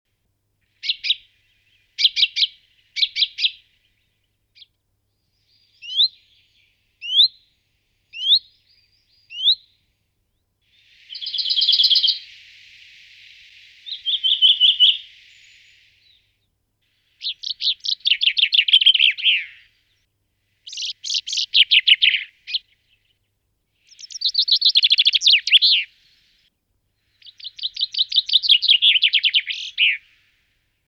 Fringilla coelebs
fringuello.mp3